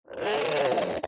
Minecraft Version Minecraft Version 1.21.5 Latest Release | Latest Snapshot 1.21.5 / assets / minecraft / sounds / mob / strider / retreat3.ogg Compare With Compare With Latest Release | Latest Snapshot
retreat3.ogg